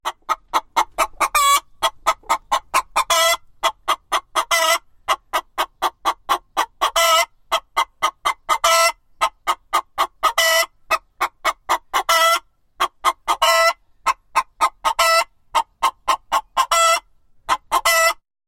Вступление перед началом песни